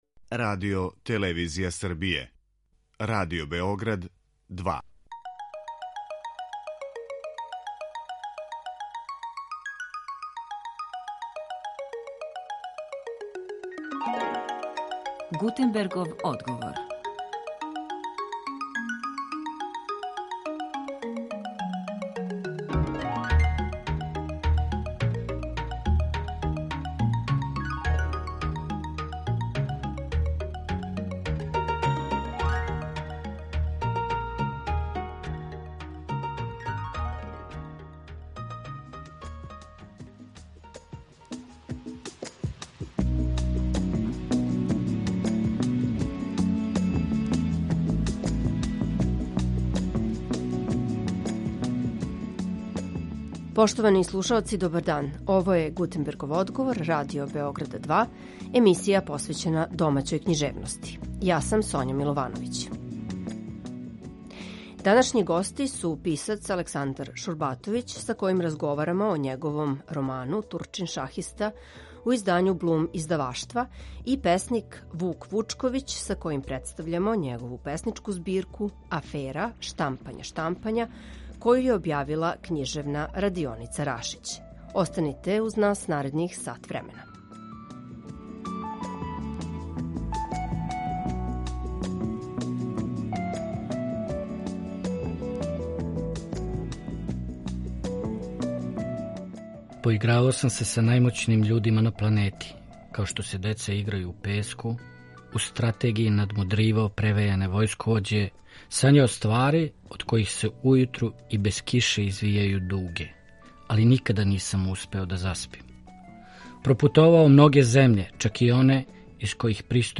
Гости Гутенберговог одговора су писац